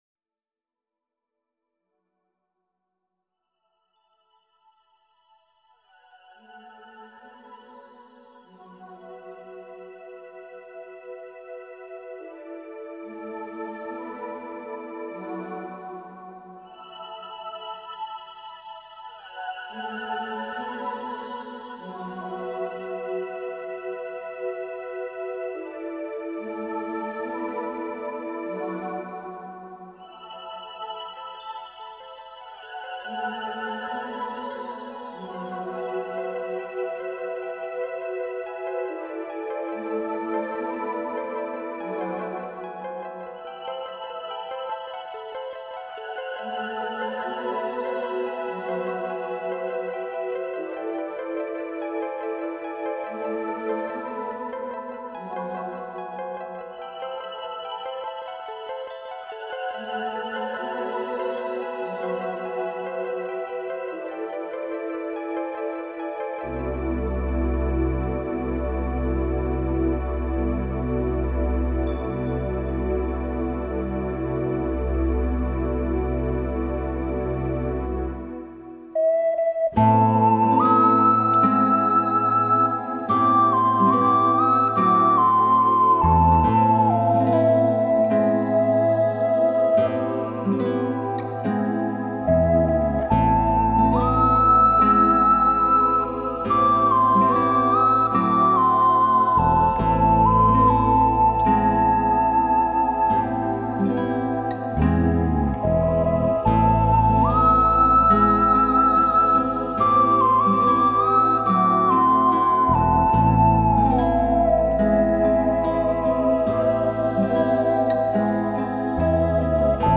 Genre: Newage.